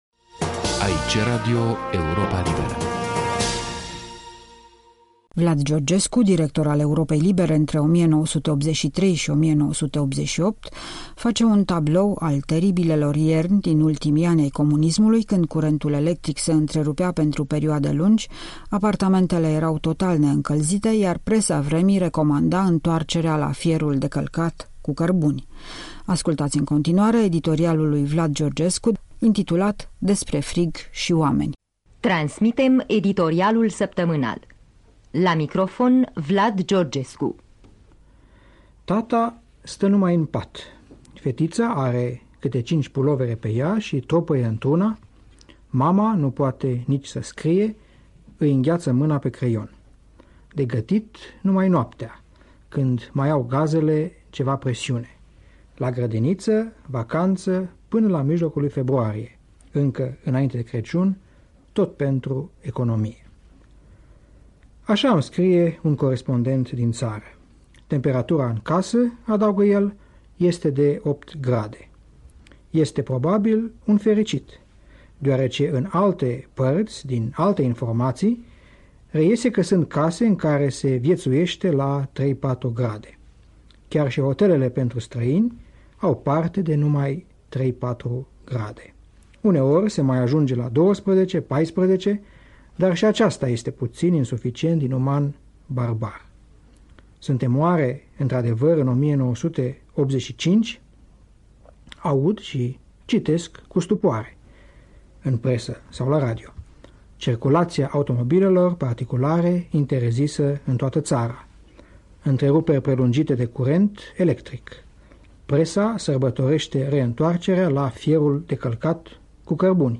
Despre frig și oameni, un editorial din 1985